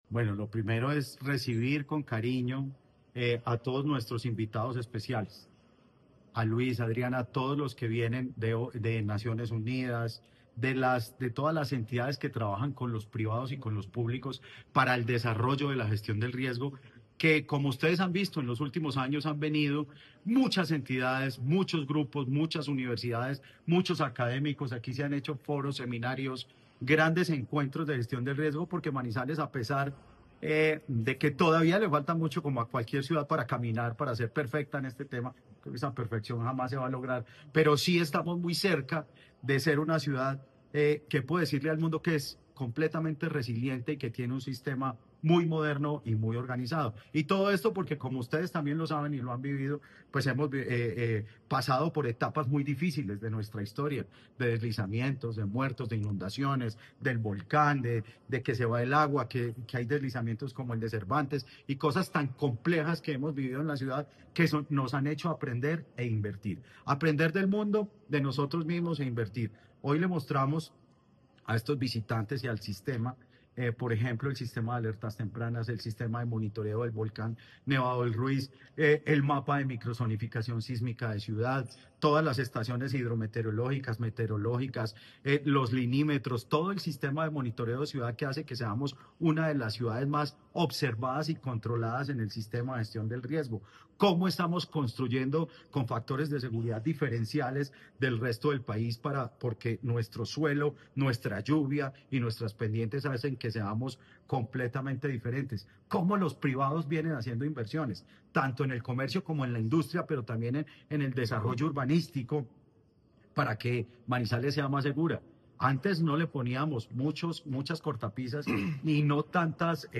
Jorge Eduardo Rojas, alcalde de Manizales